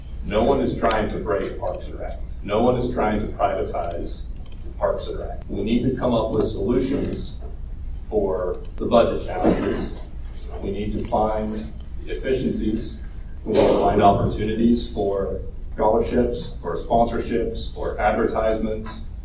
A standing-room only crowd came out Monday for a public forum on the future of Parks and Recreation programming, held at Manhattan Fire HQ.